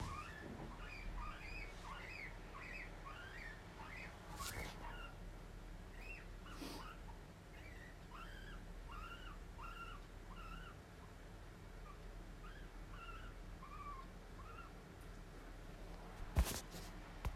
Hij piept sinds een paar dagen heel jammerlijk… en hij heeft een roze bultje in zijn oog